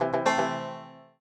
banjo_ceceac1ce.ogg